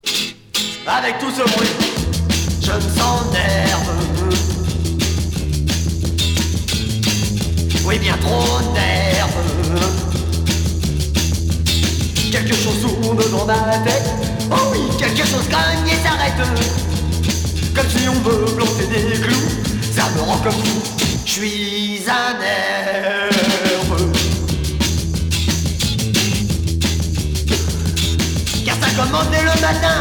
R'n'r